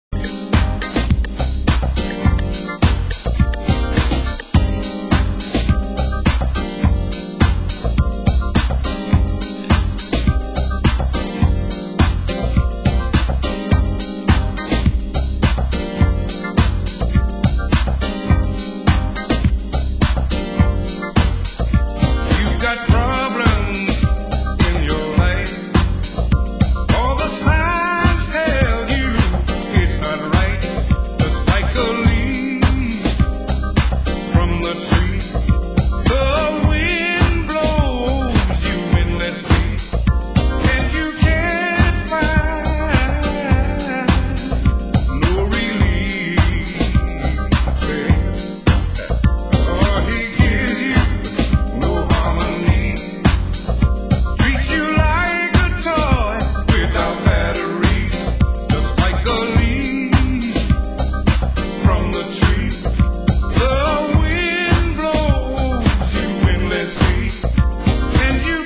Genre Soul & Funk